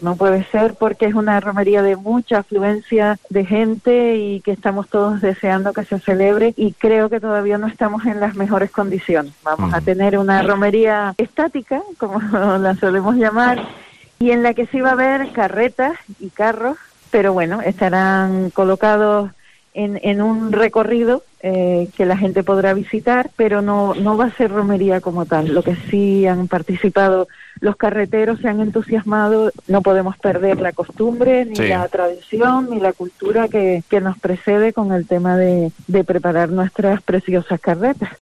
La alcaldesa de Tegueste, Ana Mena, ha explicado en los micrófonos de COPE Tenerife que habrá "una romería estática con carretas y carros, que estarán colocados en un recorrido que la gente podrá visitar".